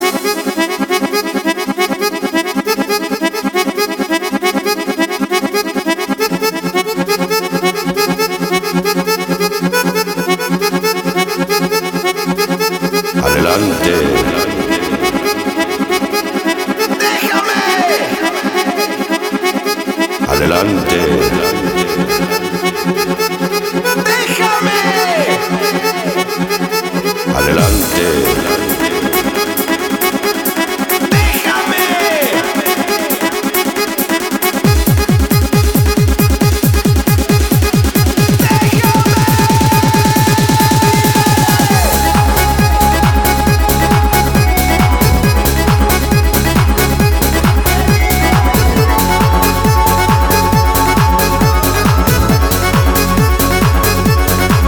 • Качество: 245, Stereo
ритмичные
красивая мелодия
Eurodance
techno
Eurotrance